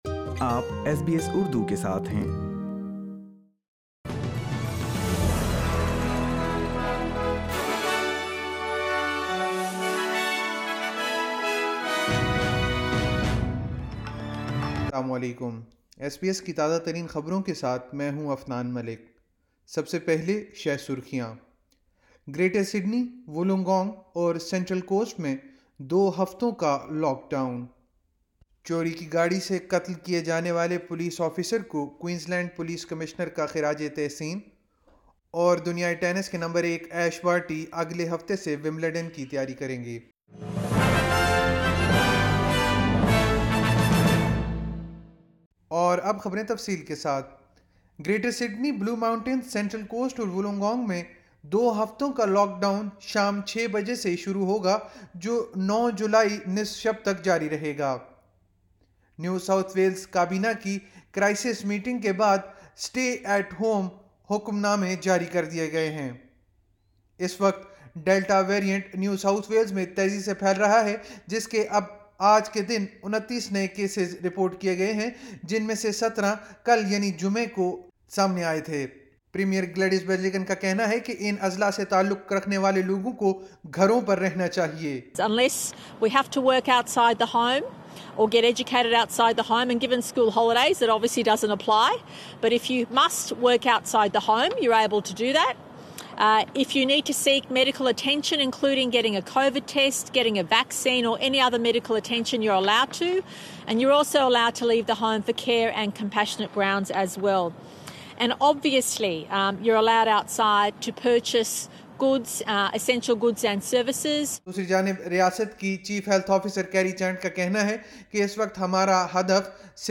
SBS Urdu News 26 June 2021